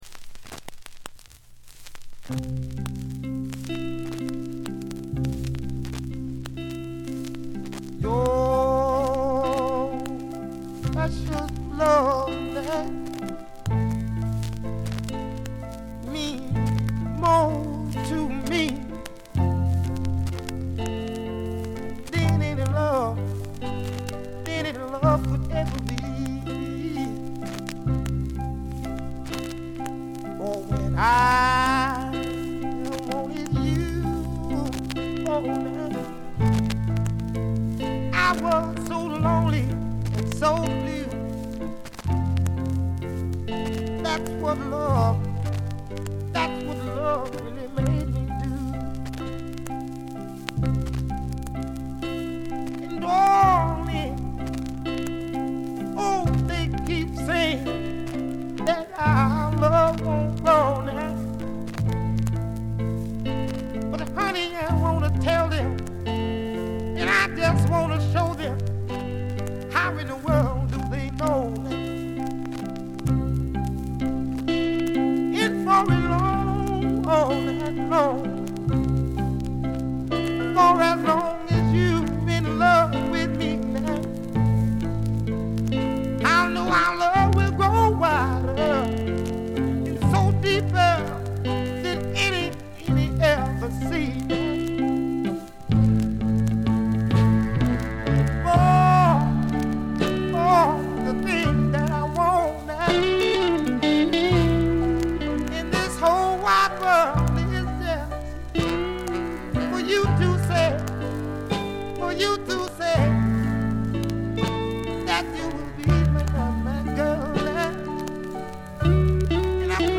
全編通じて強めのバックグラウンドノイズが出ます。
音質はよくありませんがコレクターの方はお見逃しなく。
試聴曲は現品からの取り込み音源です。
vocals